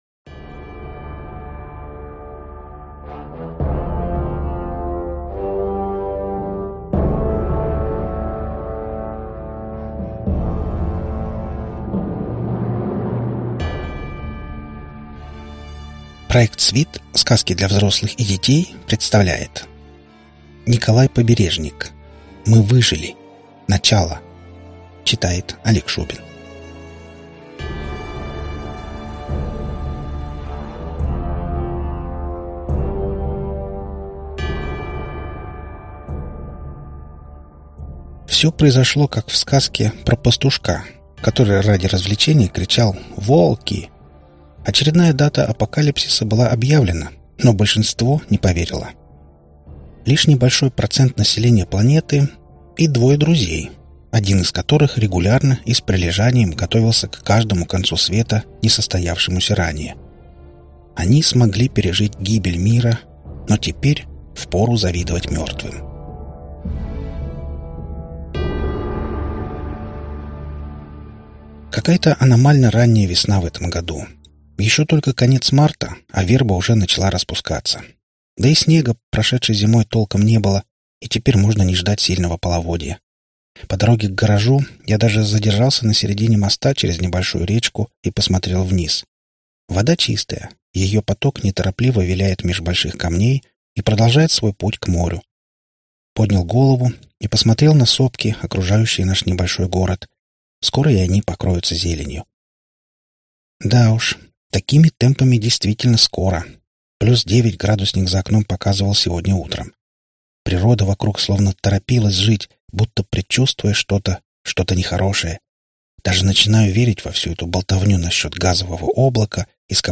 Аудиокнига Мы выжили! Начало | Библиотека аудиокниг
Прослушать и бесплатно скачать фрагмент аудиокниги